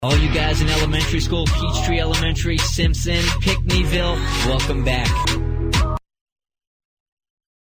Example of School Shout-Out: